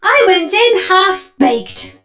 pearl_die_vo_02.ogg